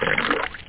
POUR.mp3